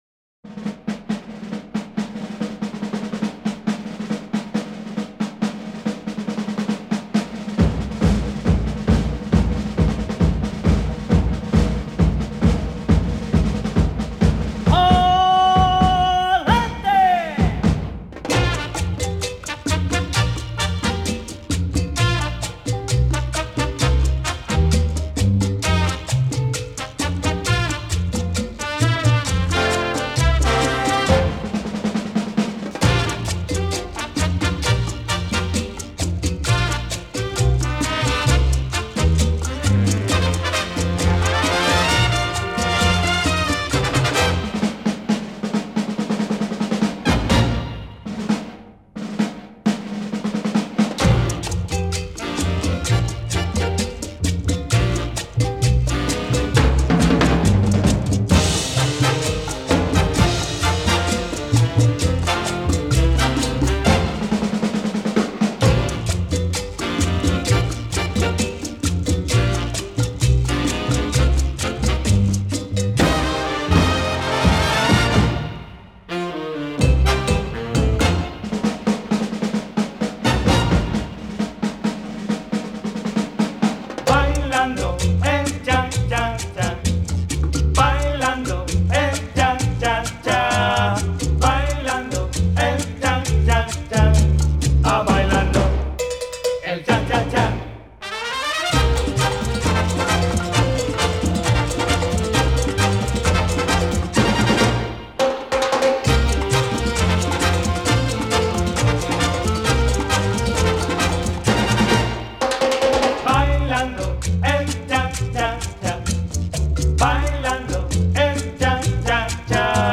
本辑收录最IN最火最到位的恰恰舞曲，让你伴着活泼，诙谐，热情，奔放，节奏欢快的舞曲尽情挥洒。